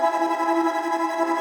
SaS_MovingPad05_170-E.wav